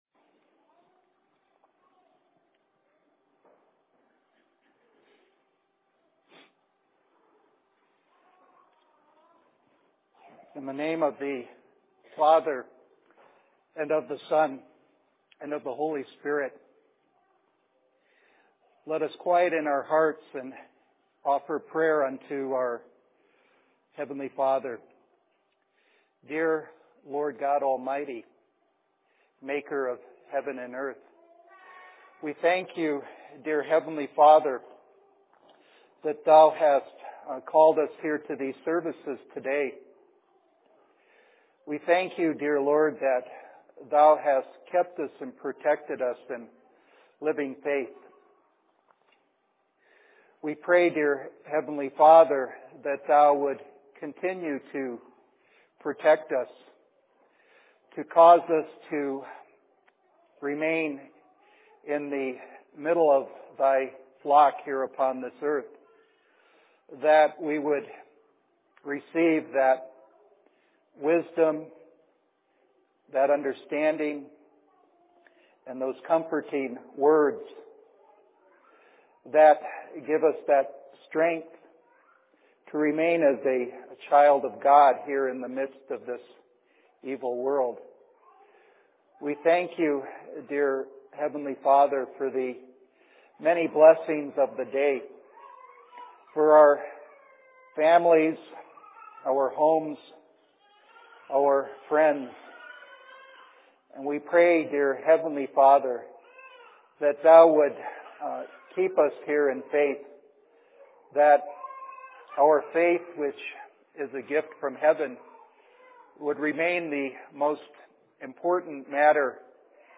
Sermon in Phoenix 08.03.2009